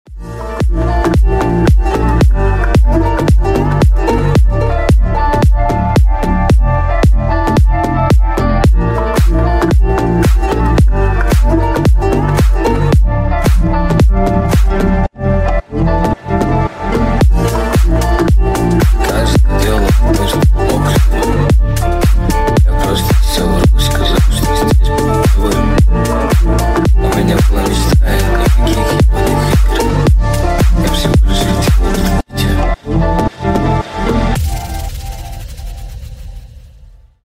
Громкие Рингтоны С Басами
Рингтоны Ремиксы » # Танцевальные Рингтоны